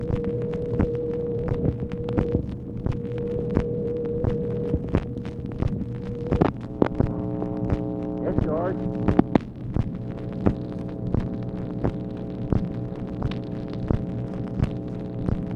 LBJ SAYS "YES, GEORGE"
Conversation with (possibly) GEORGE REEDY, April 30, 1965
Secret White House Tapes